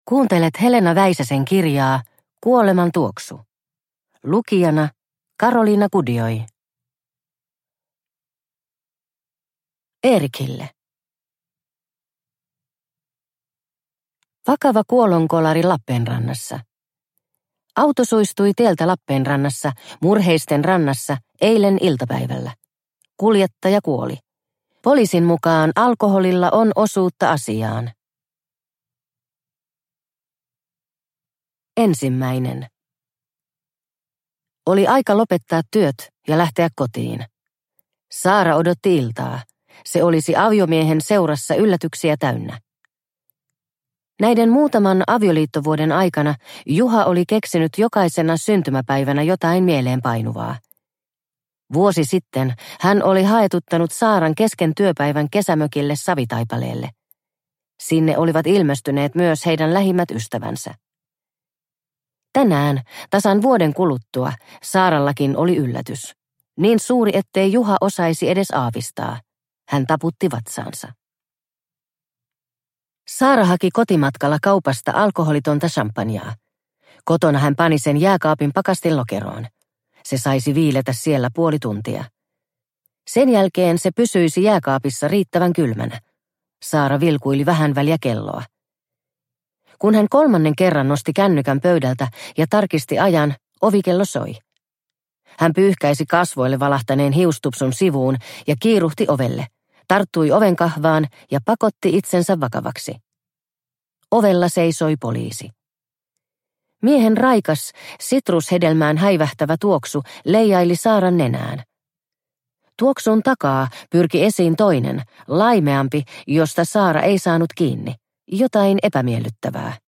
Kuoleman tuoksu – Ljudbok – Laddas ner